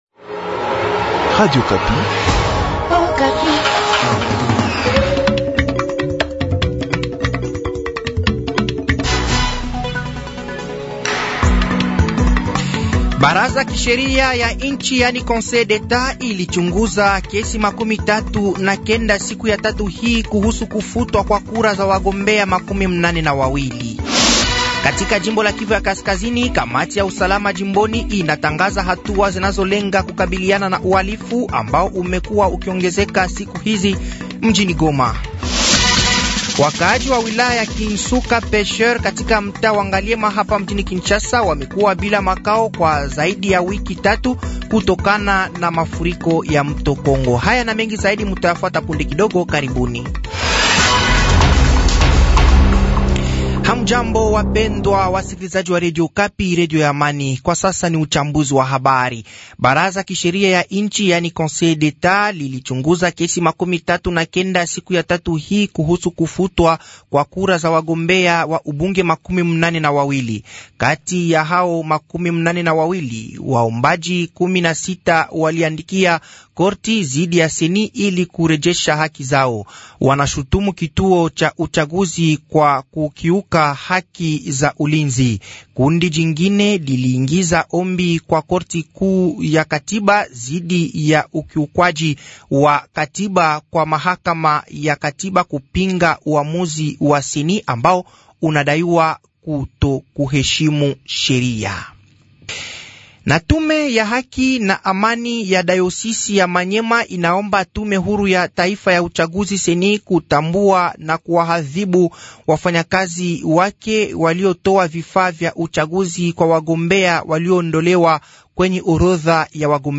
Journal Soir
Habari za siku ya tatu jioni tarehe 10/10/2024